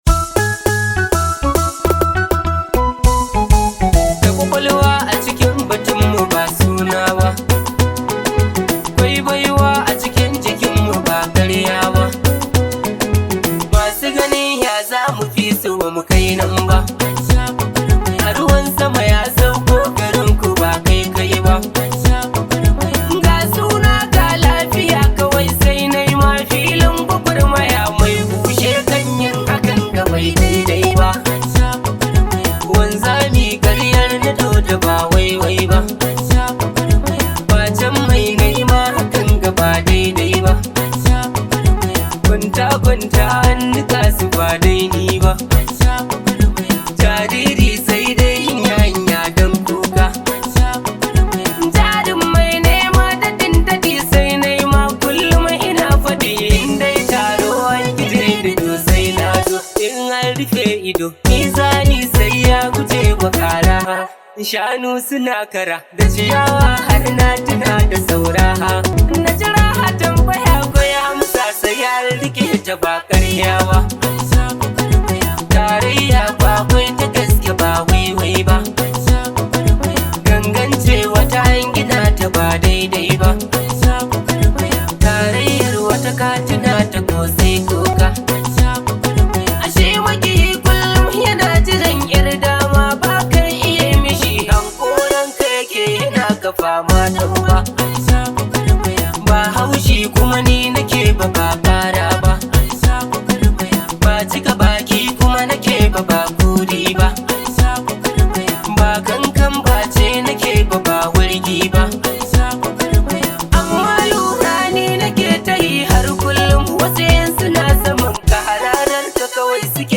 much appreciated hausa song known as
high vibe hausa song